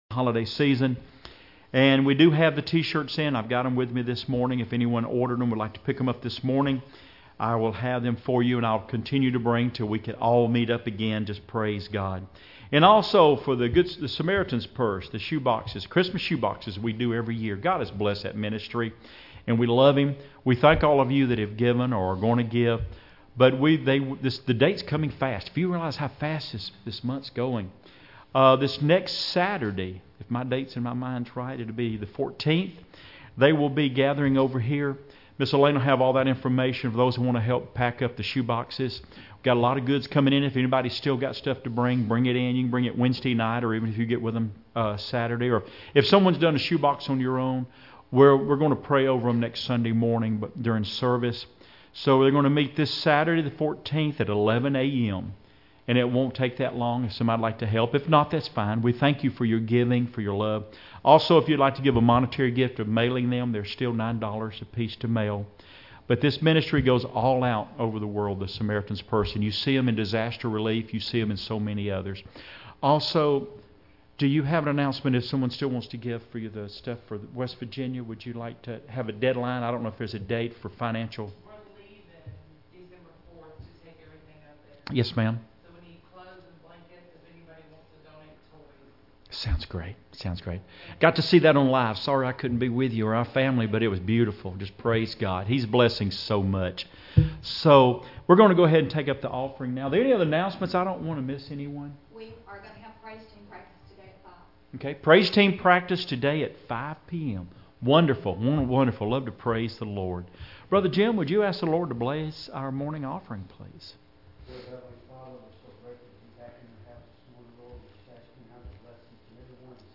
Mark 8:34-37 Service Type: Sunday Morning Services Topics